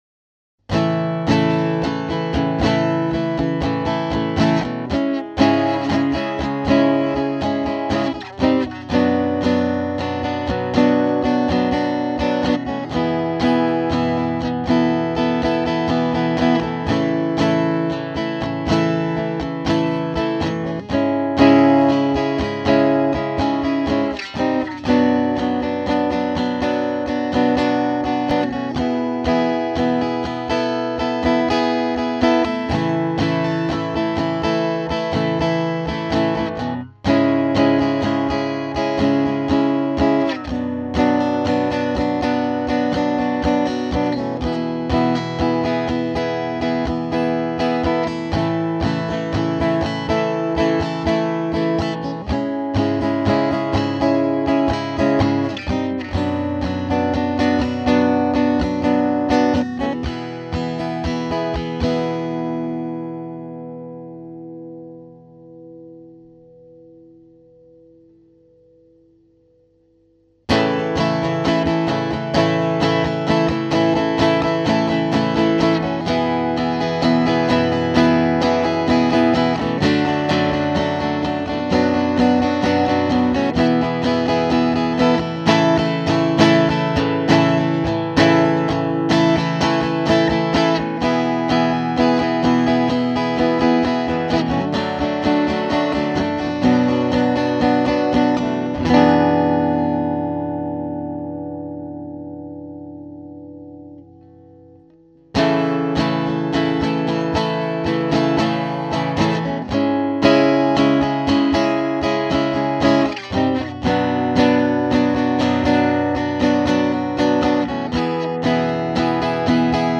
Same song with no vocals!